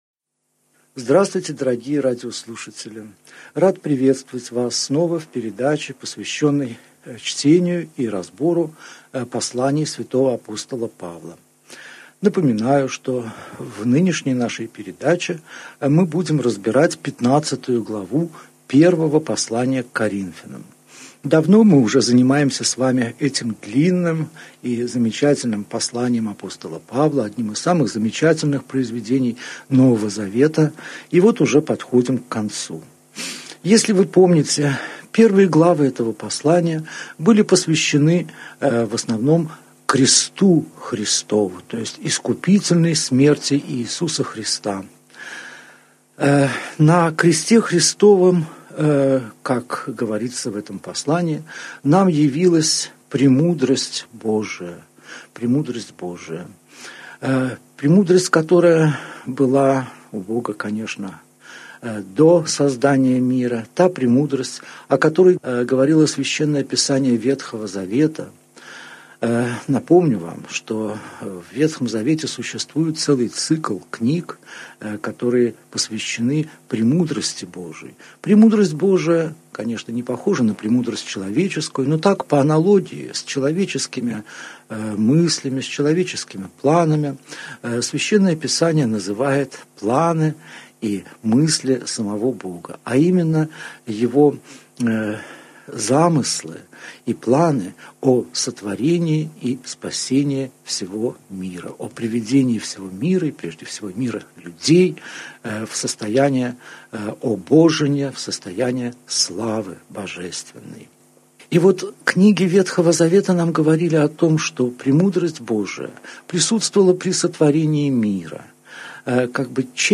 Аудиокнига Беседа 36. Первое послание к Коринфянам. Глава 15 | Библиотека аудиокниг